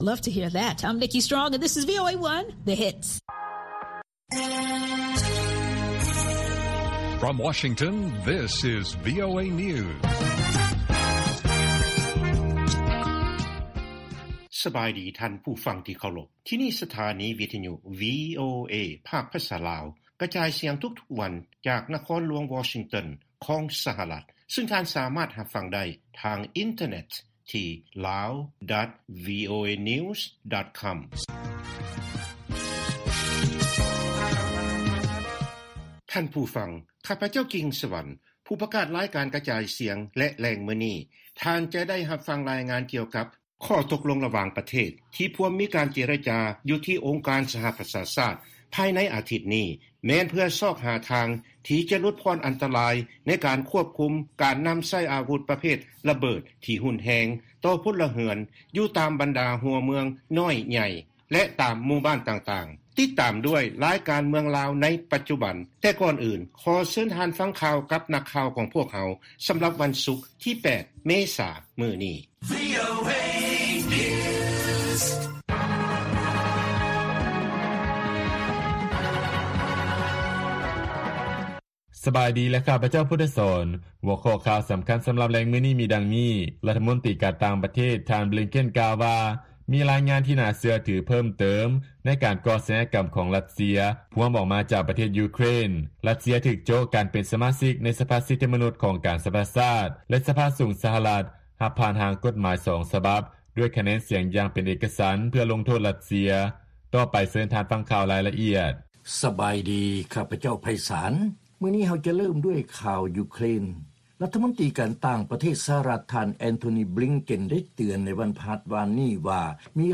ລາຍການກະຈາຍສຽງຂອງວີໂອເອ ລາວ: ທ່ານບລິງເກັນ ກ່າວວ່າ ມີລາຍງານທີ່ໜ້າເຊື່ອຖືເພີ້ມຕື່ມ ໃນການກໍ່ອາຊະຍາກຳຂອງຣັດເຊຍ ພວມອອກມາ ຈາກປະເທດຢູເຄຣນ